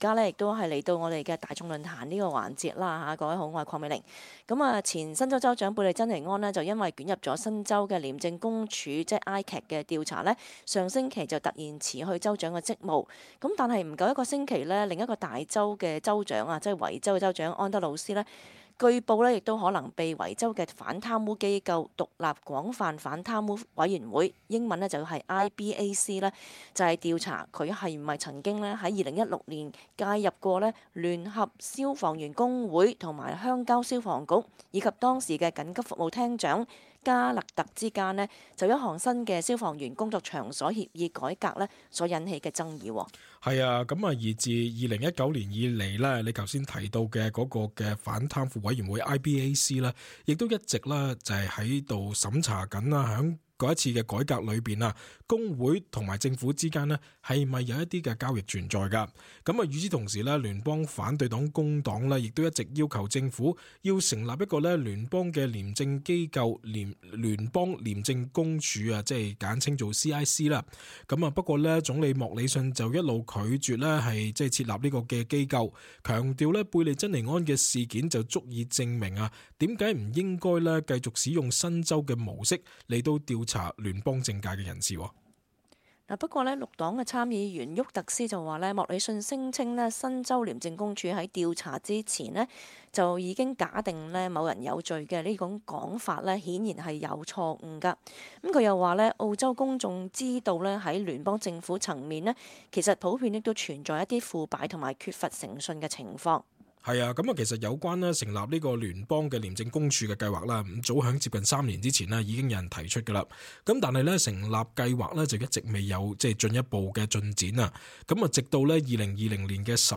talkback_-_oct_7_upload.mp3